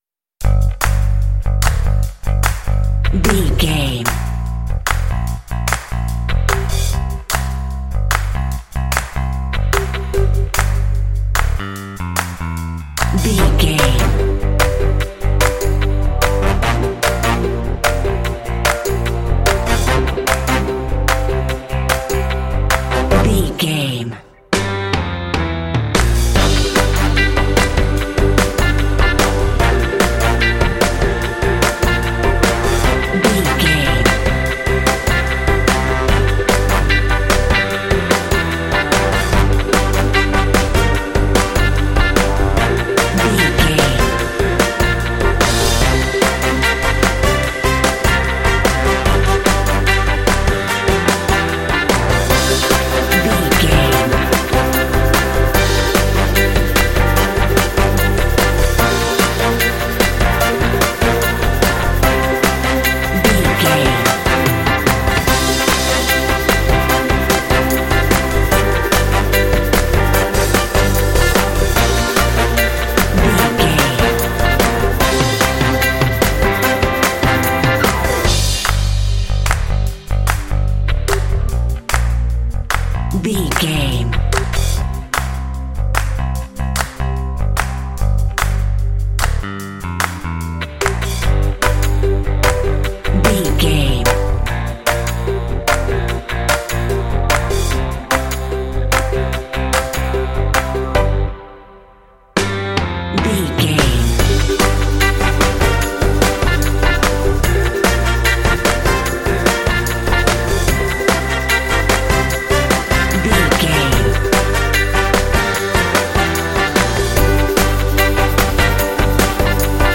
Aeolian/Minor
fun
bright
lively
sweet
brass
horns
electric organ
drums
bass guitar
modern jazz
pop